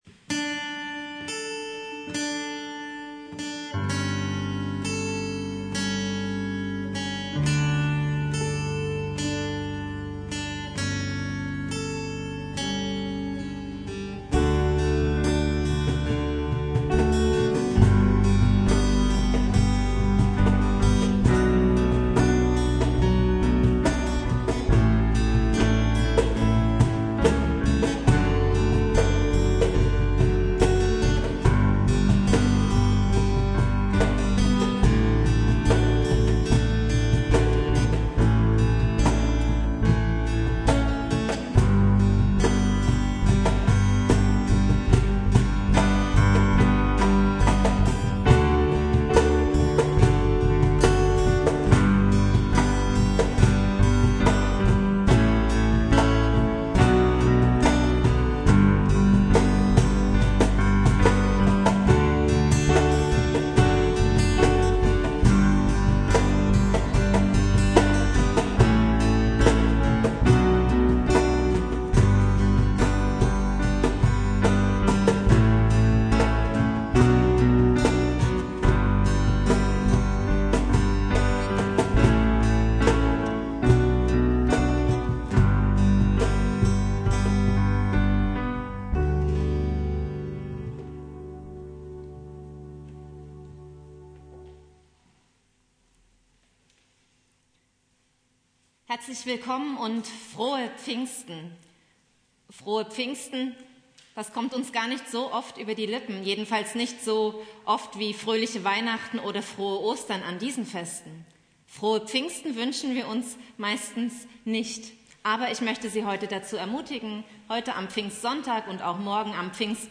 Predigt
Pfingstsonntag
Gottesdienst komplett